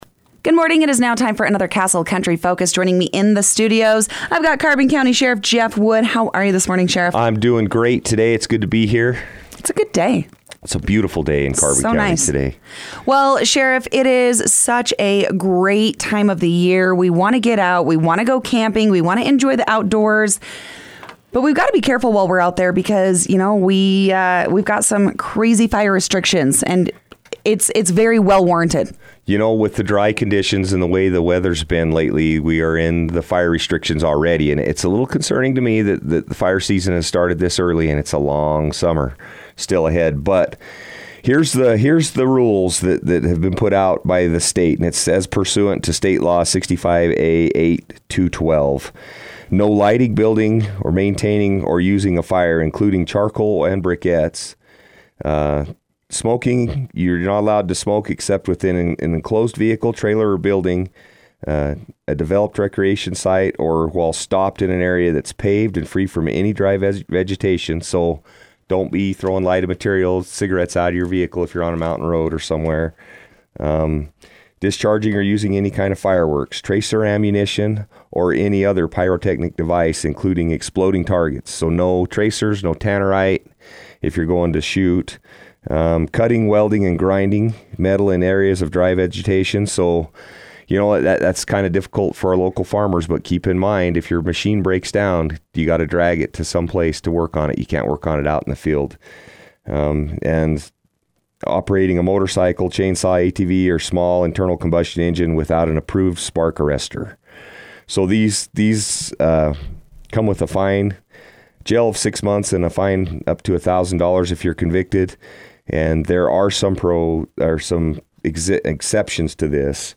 Carbon County Sheriff weekly update discusses Fire Restrictions